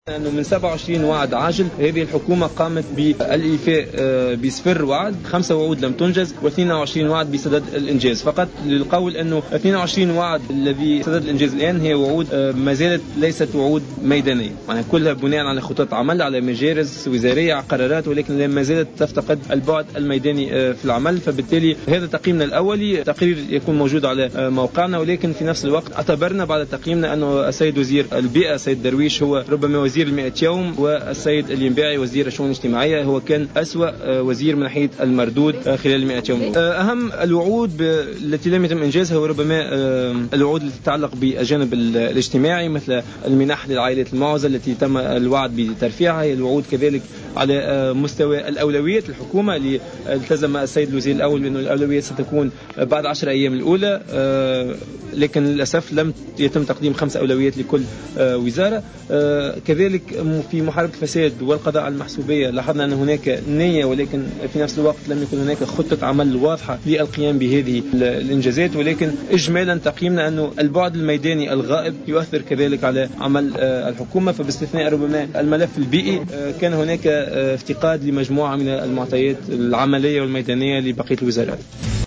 خلال ندوة صحفية